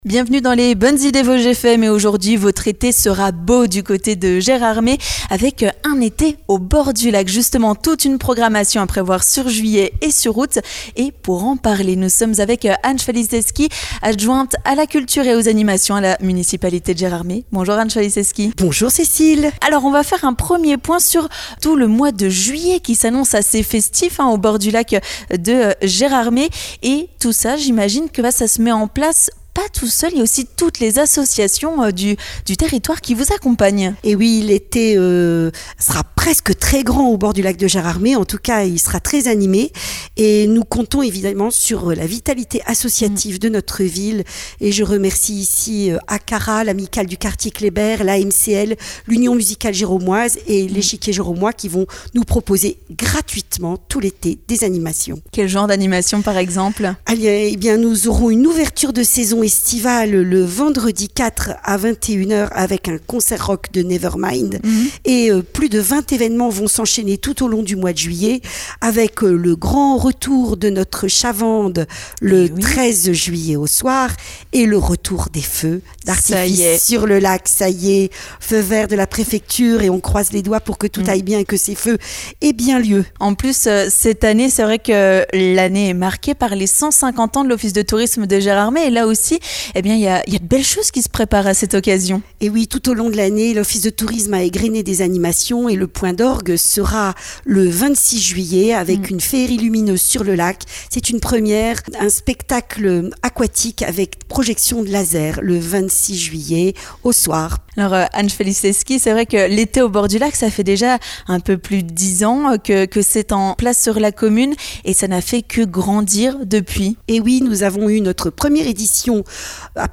On fait le point sur le mois de juillet avec Anne Chwaliszewski, adjointe à la culture et aux animations à la municipalité de Gérardmer.